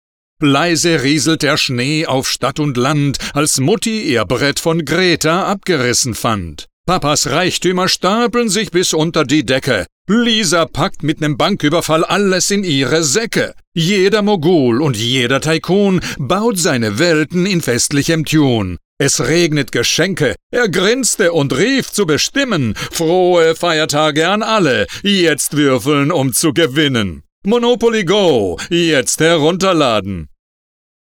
Male
Adult (30-50), Older Sound (50+)
Main Demo
Male Voice Over Talent